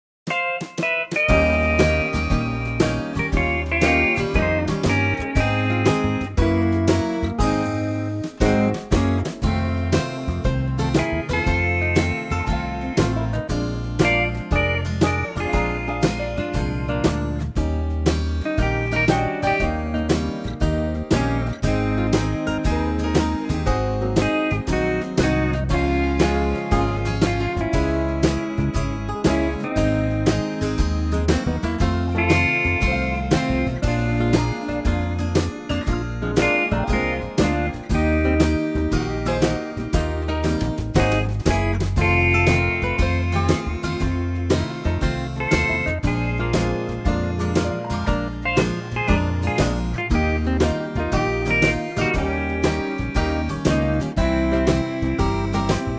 Unique Backing Tracks
key - Ab - vocal range - Gb to G
Superb Country arrangement